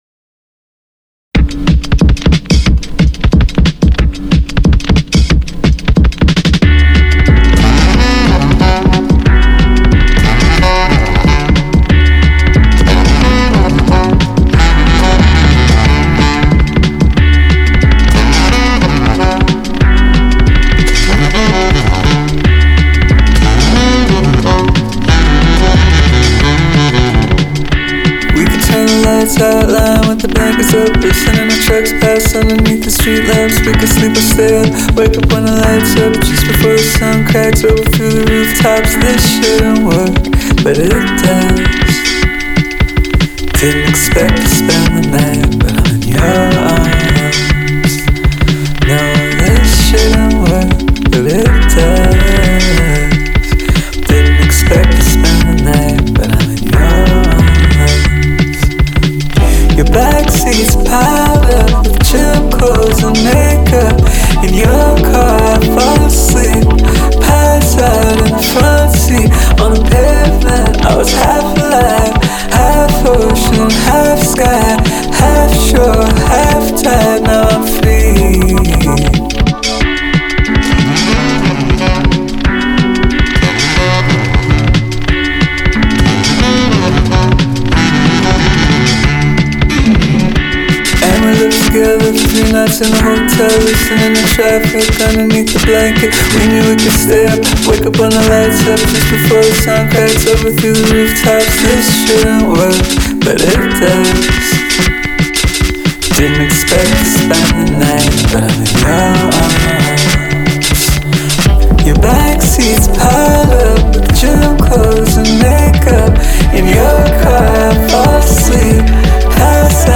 Genre : Alternative, Pop